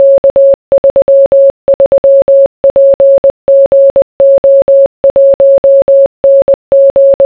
Morse 10 points Cryptography • Харуул Занги U18: 2017 • unknown • Solved: 88 Сонсож чадах уу?
morse.wav